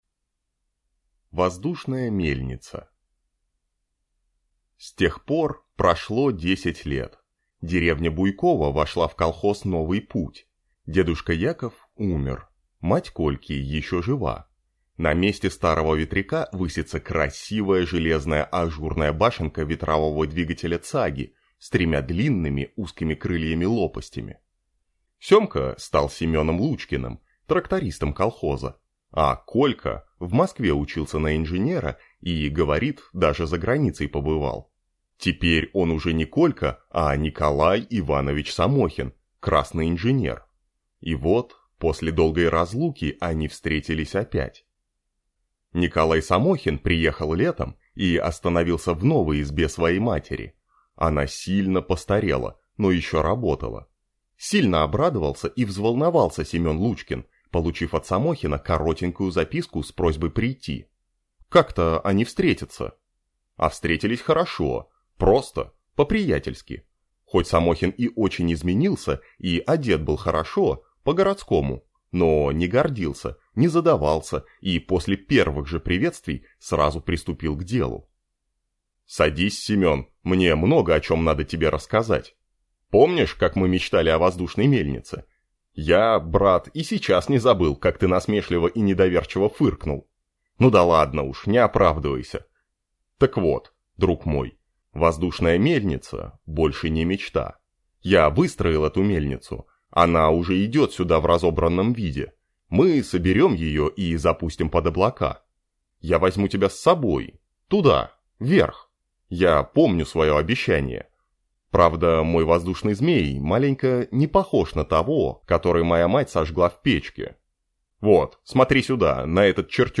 Аудиокнига Воздушный змей | Библиотека аудиокниг
Прослушать и бесплатно скачать фрагмент аудиокниги